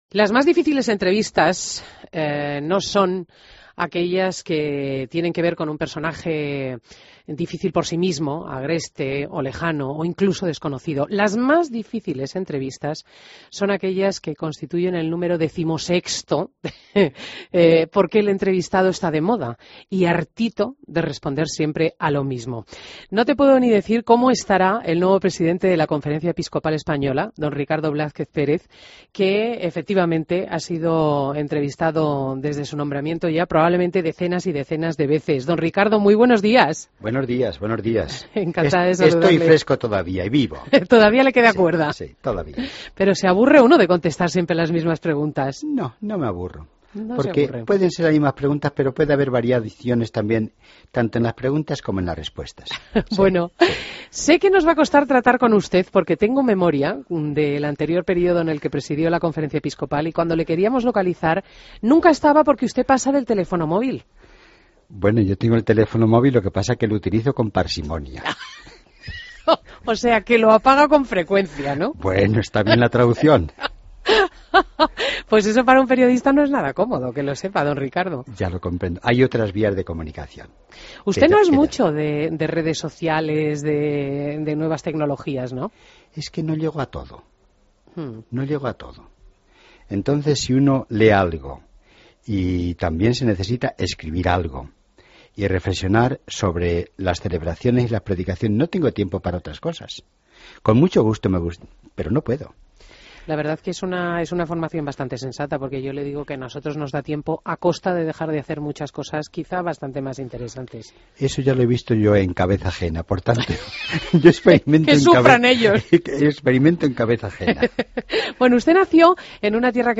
AUDIO: Entrevista a Monseñor Ricardo Blázquez en Fin de Semana COPE